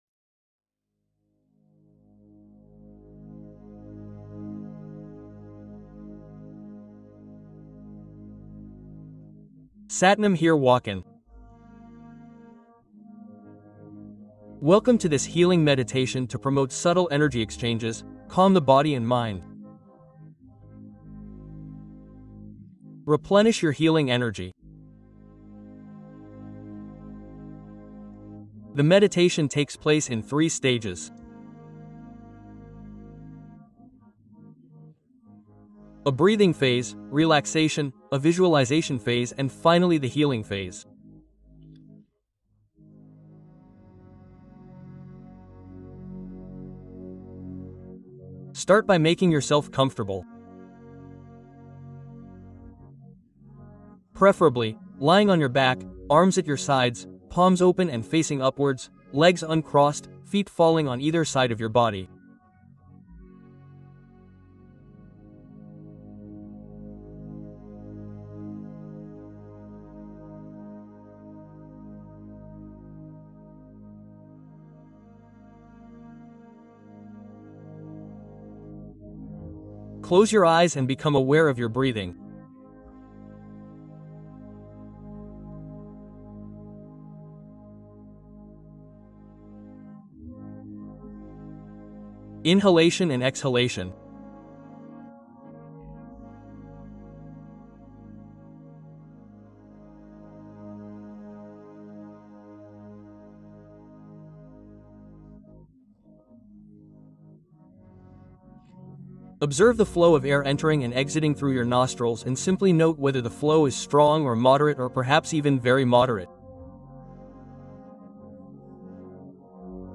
Bols tibétains : relâcher les peurs et fluidifier l’énergie